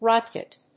/rάtgʌt(米国英語), rˈɔtgʌt(英国英語)/